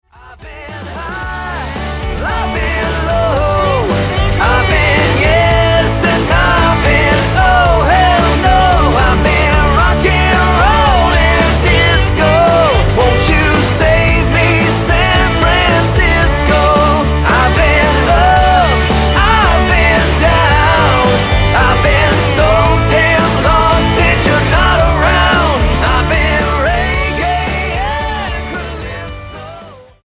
NOTE: Background Tracks 1 Thru 9